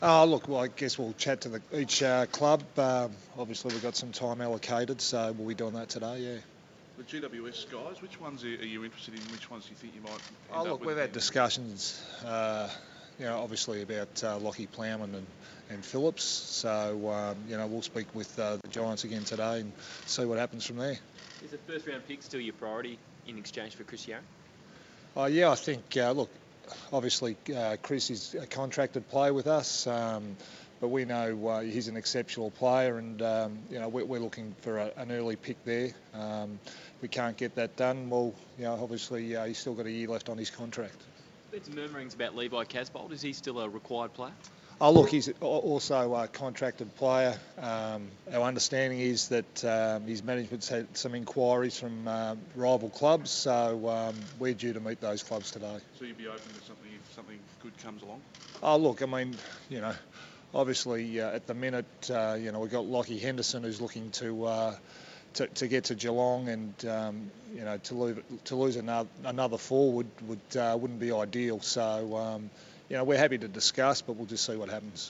Carlton list manager Stephen Silvagni addressed the media on day one of the AFL Trade Period.